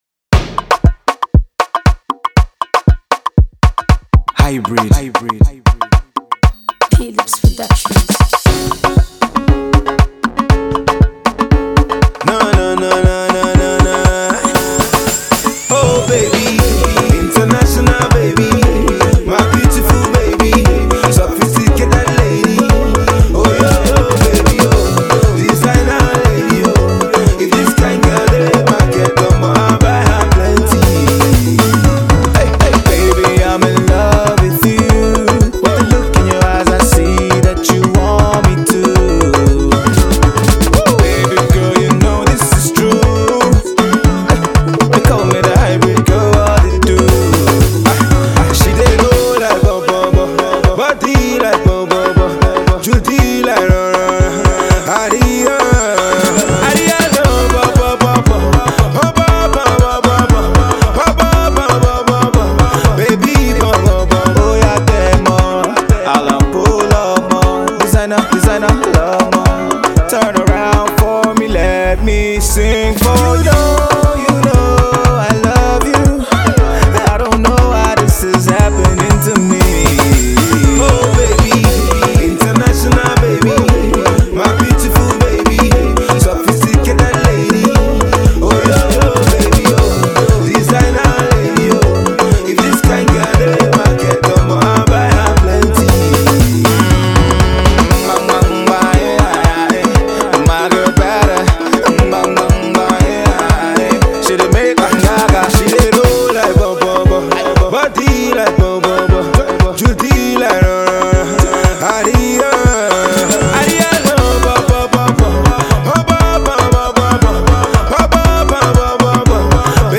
this hot new banger